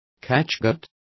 Complete with pronunciation of the translation of catguts.